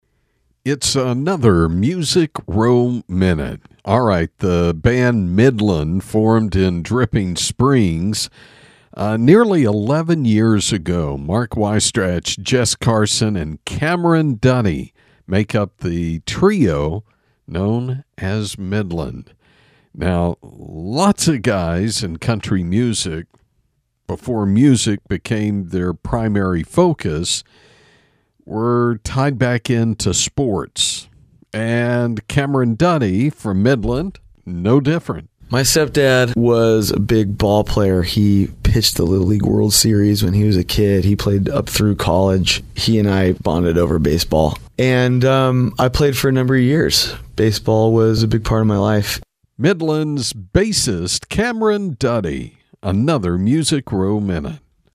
Music Row Minute is a daily radio feature on 106.1FM KFLP